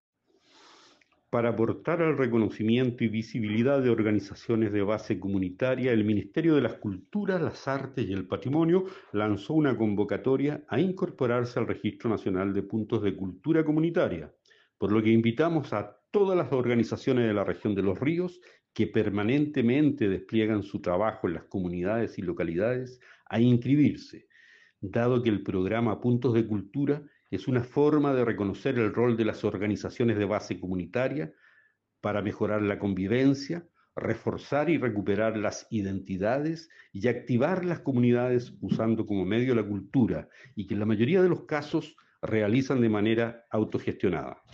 Seremi-de-las-Culturas-Oscar-Mendoza_Registro-Puntos-de-Cultura.mp3